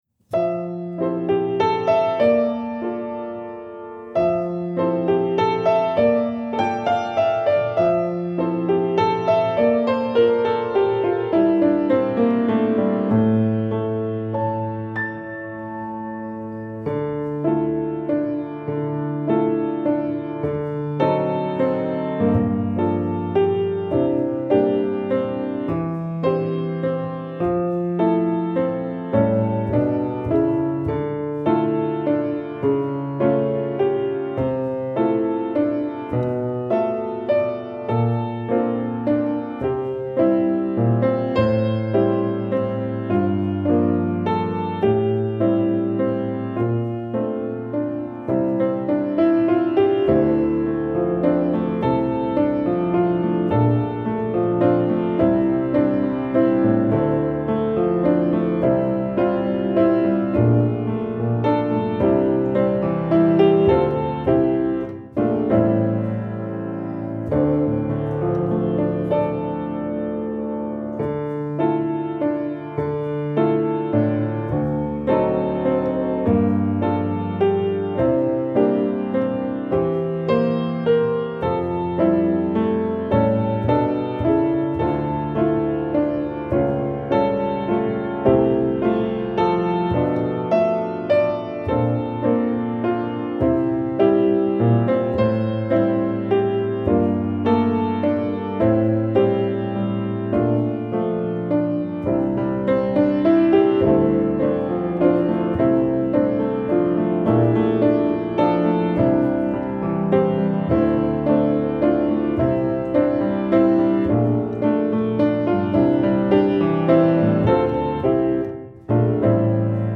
ピアノ伴奏（Dm）